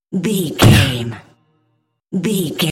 Electronic stab hit trailer
Sound Effects
Atonal
heavy
intense
dark
aggressive